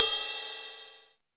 Crashes & Cymbals
Ride_01.wav